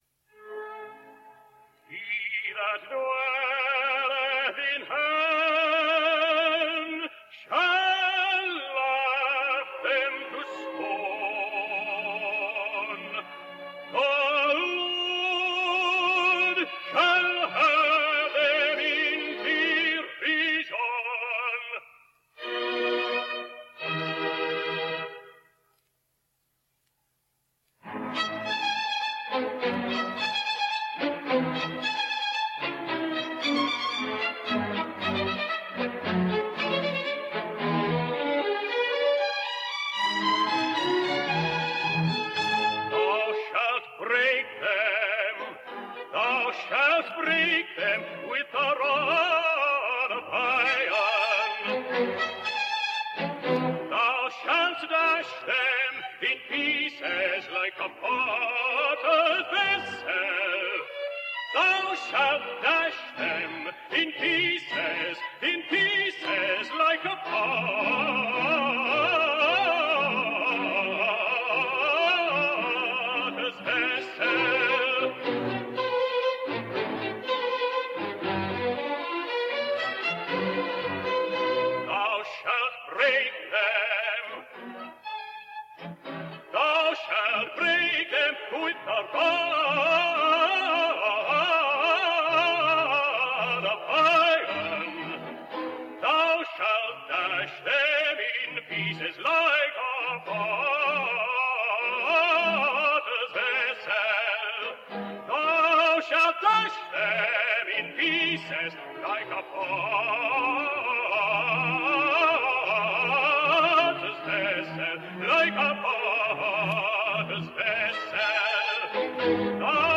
Vickers can’t help creating an atmosphere of intense personal involvement.
Jon Vickers with a characteristically sturdy rendering of one of the tenor’s solos from Handel’s Messiah.